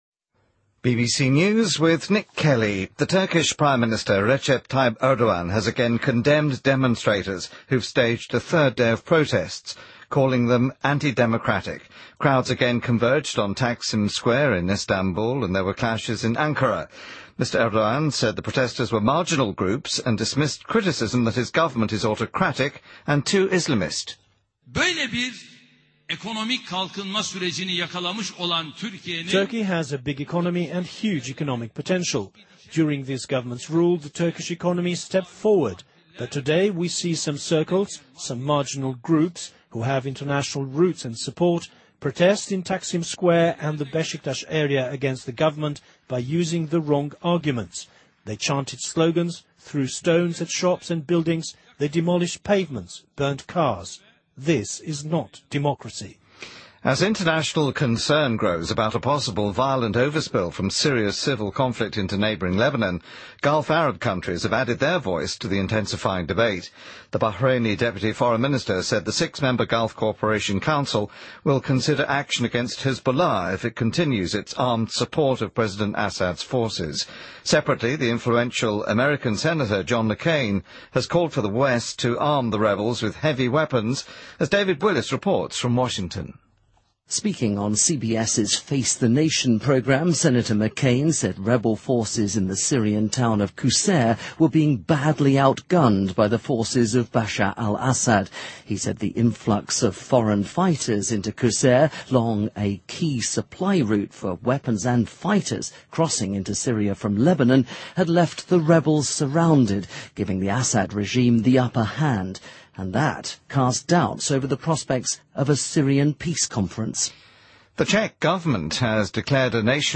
BBC news,2013-06-03